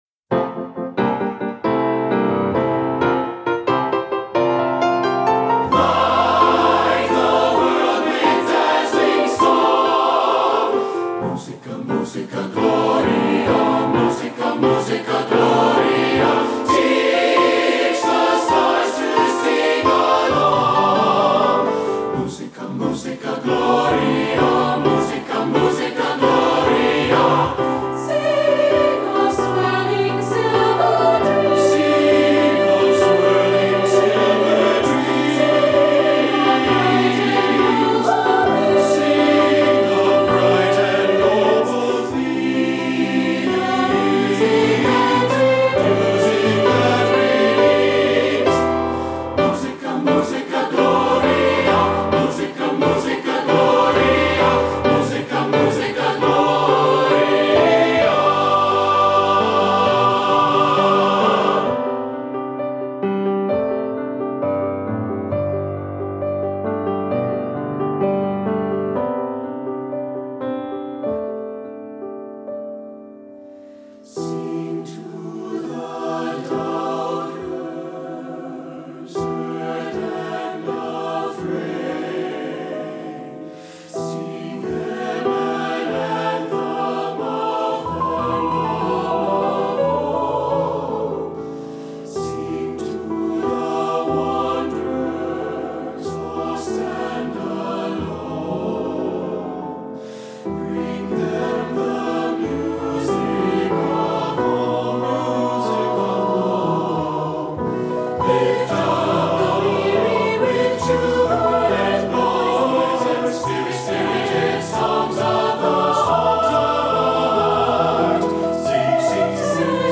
Choral Concert/General
SATB Audio